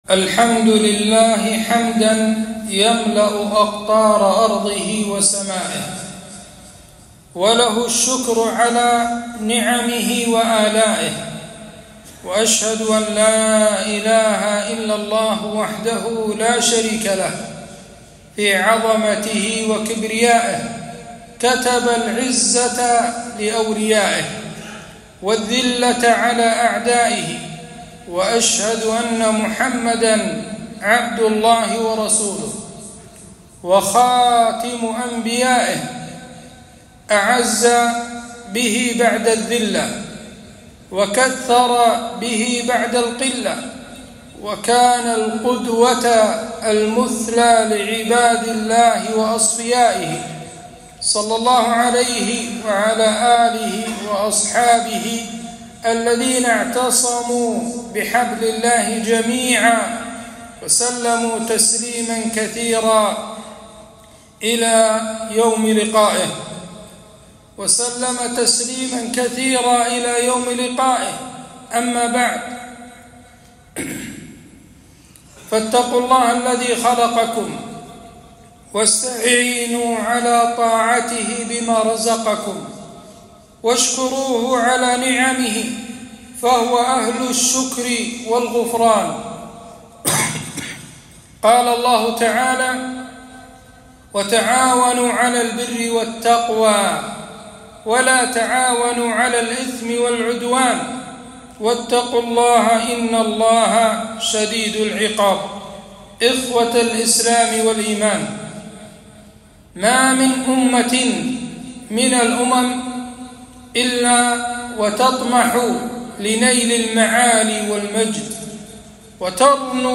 خطبة - طاعة ولي الأمر وأثرها في اجتماع الكلمة